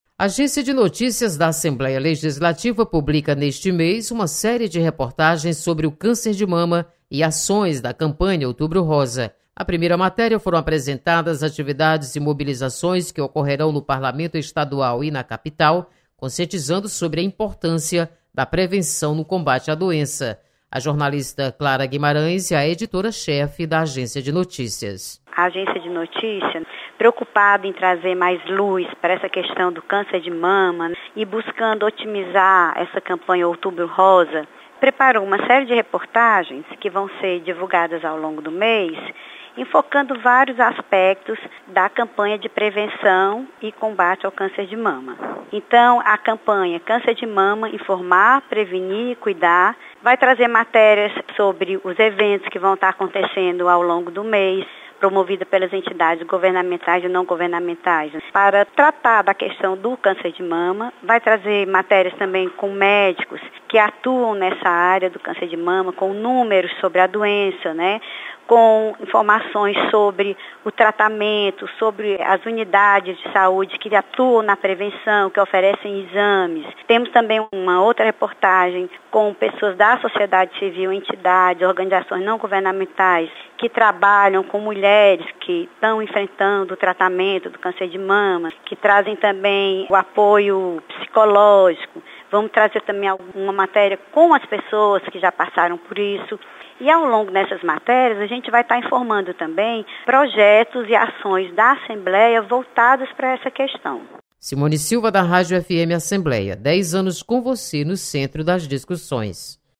Site da Assembleia apresenta reportagens sobre Outubro Rosa.